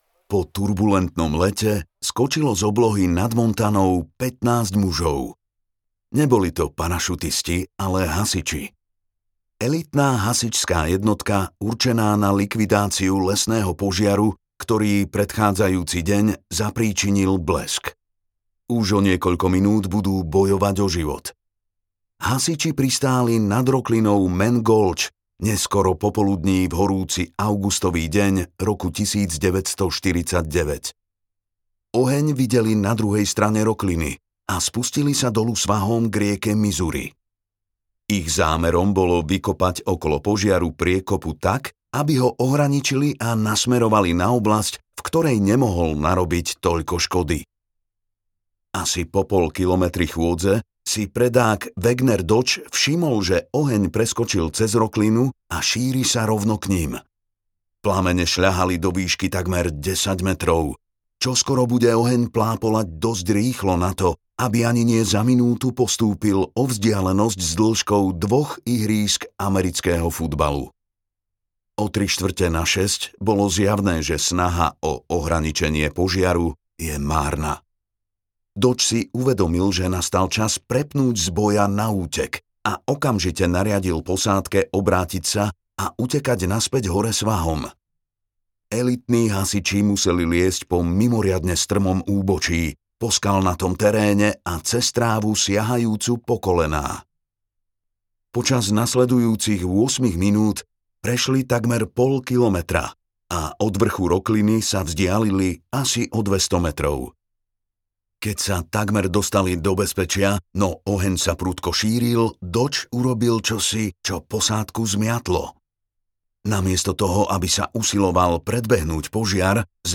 Ešte si to premysli audiokniha
Ukázka z knihy